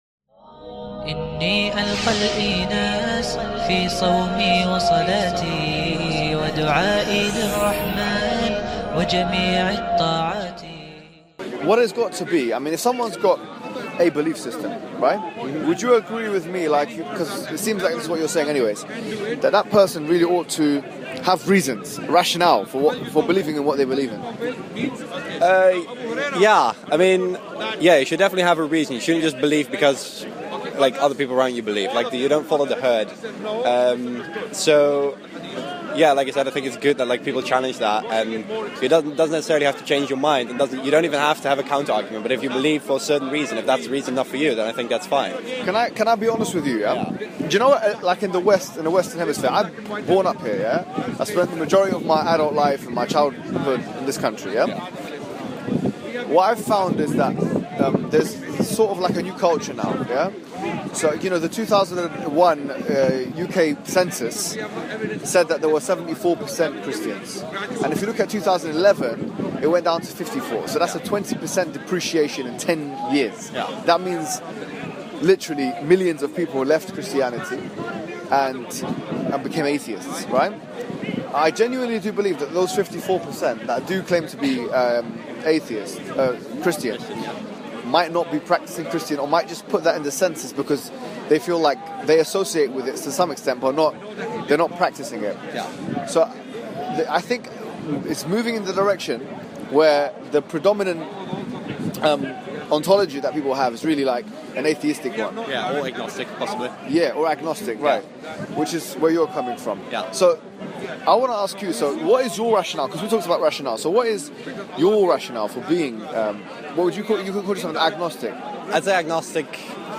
Agnost discussion with Muslim｜｜ Mohammed Hijab.mp3